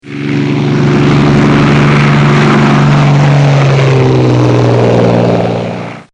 Звуки пропеллера
Гул мотора с пропеллером у самолета